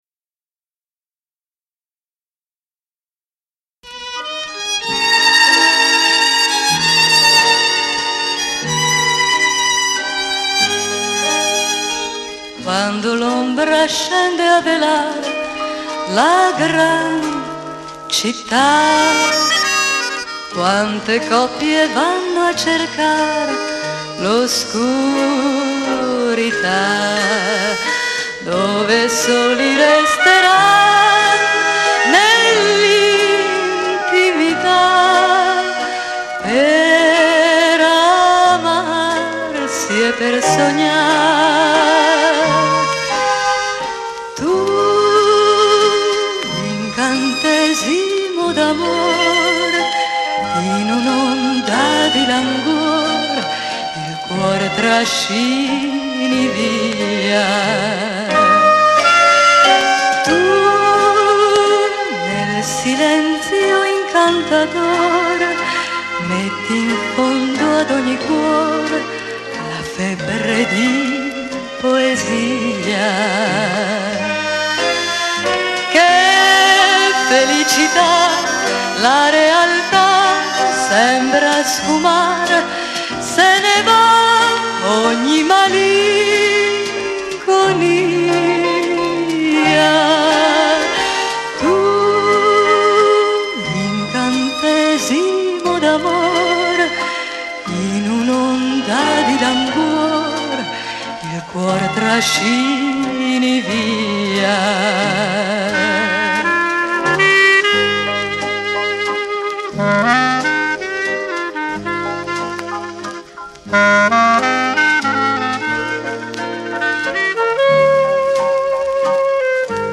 VALZER INGLESE